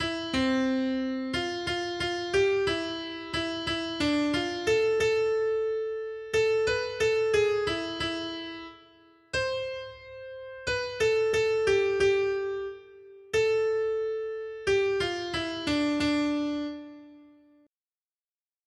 Noty Štítky, zpěvníky ol277.pdf responsoriální žalm Žaltář (Olejník) 277 Skrýt akordy R: Po své pravici máš královnu ozdobenou ofirským zlatem. 1.